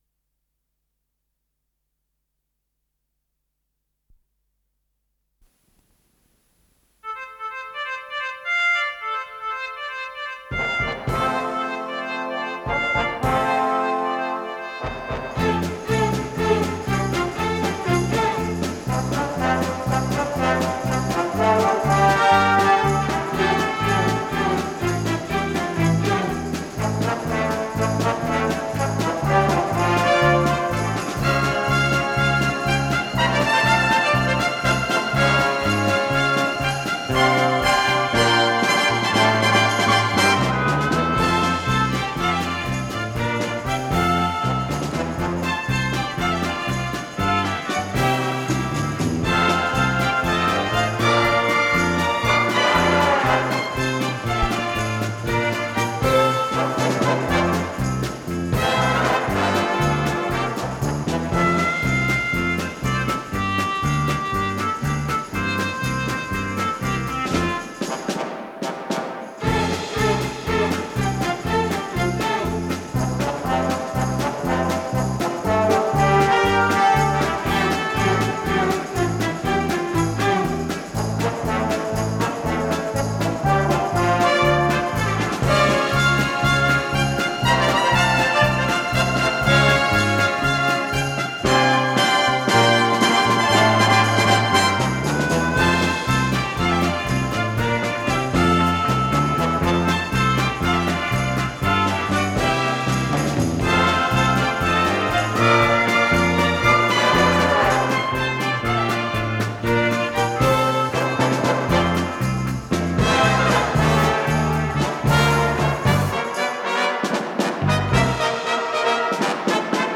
с профессиональной магнитной ленты
ПодзаголовокЗаставка, фа мажор